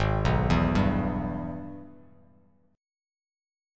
AbmM7#5 chord